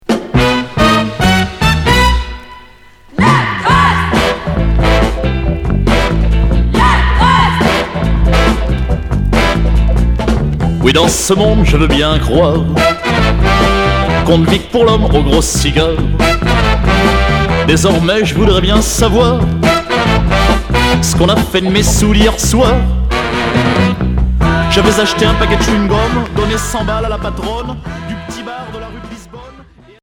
Chanteur 60's Unique EP retour à l'accueil